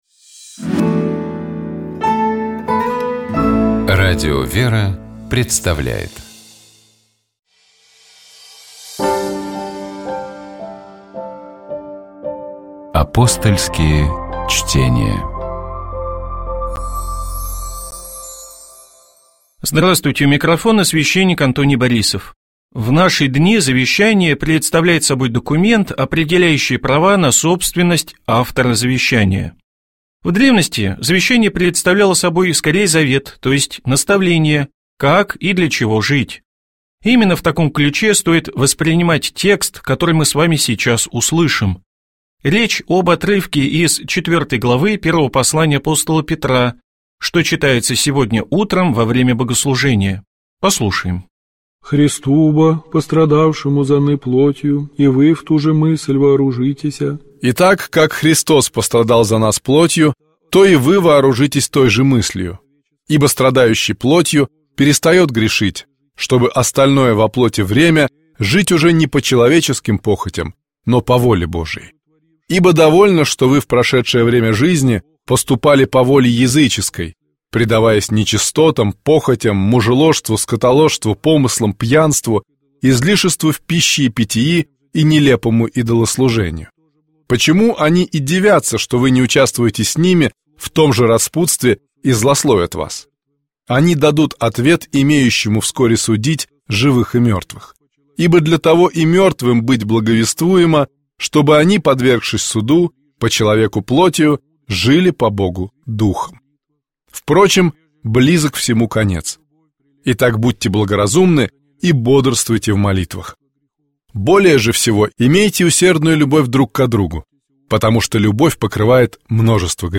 Апостольские чтения